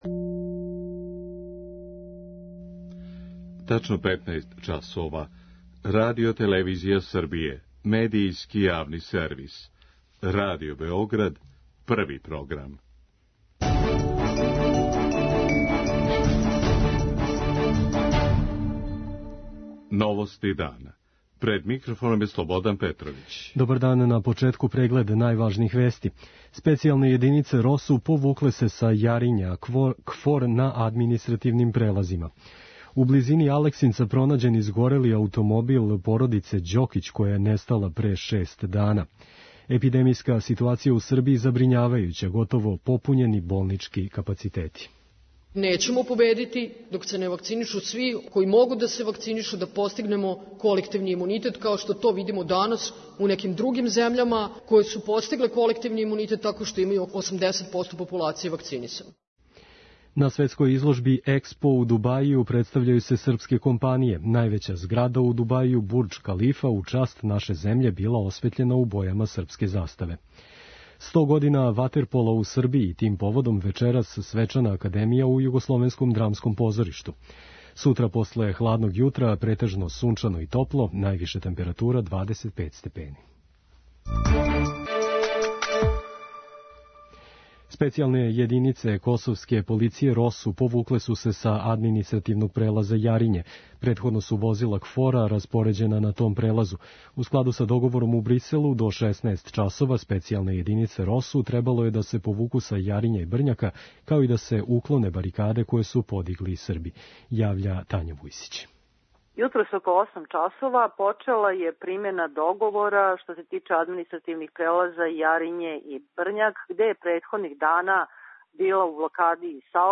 централна информативна емисија Првог програма Радио Београда